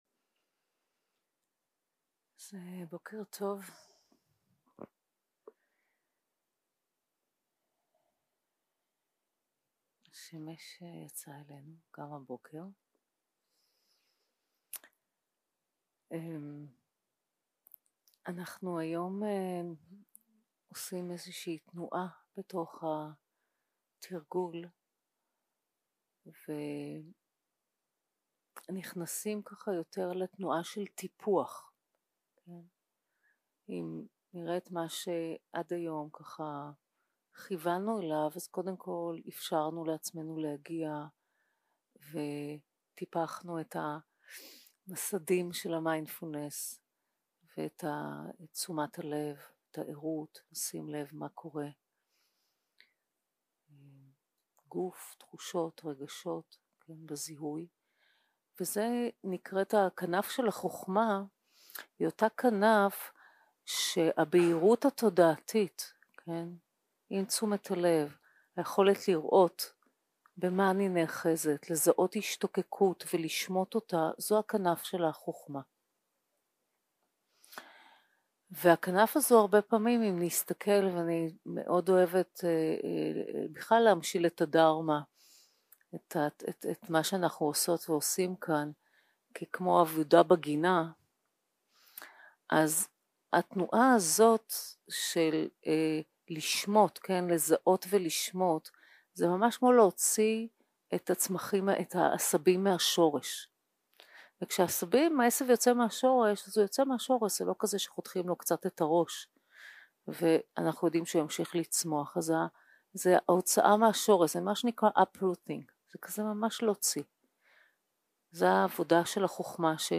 יום 5 - הקלטה 11 - בוקר - הנחיות למדיטציה - טיפוח גינת התודעה - Kindness 0:00 0:00 סוג ההקלטה: Dharma type: Guided meditation שפת ההקלטה: Dharma talk language: Hebrew